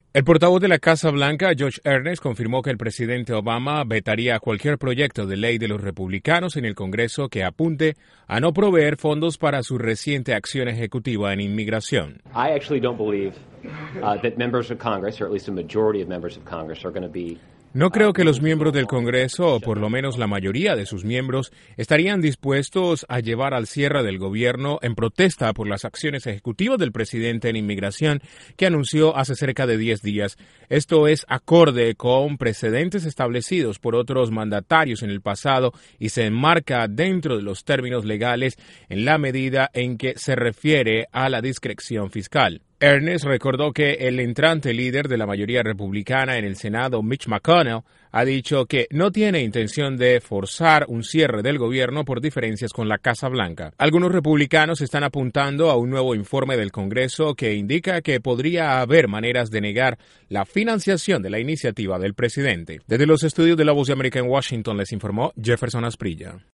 Legisladores republicanos están evaluando cómo responder a la acción ejecutiva del presidente en inmigración con leyes que podrían resultar en un cierre del gobierno. Desde la Voz de América en Washington informa